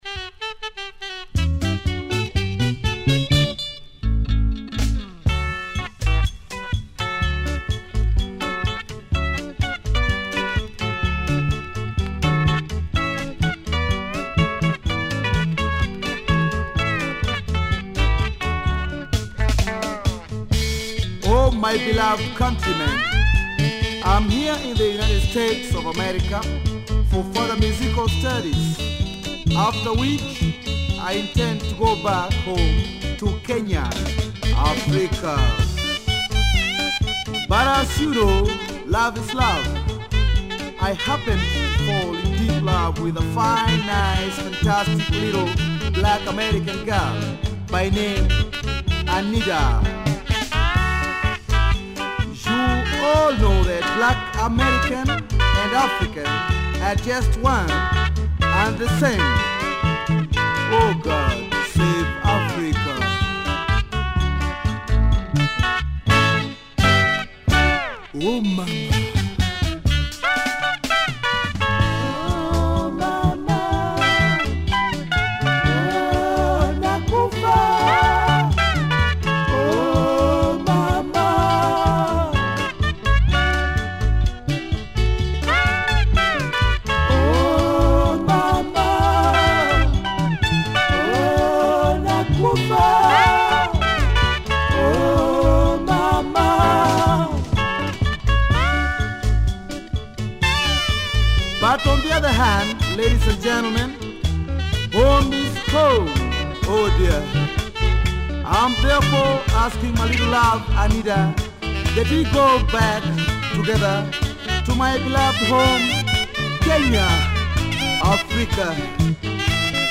Legendary Orchestra here
nice sax
Instrumental flip, check it out!!!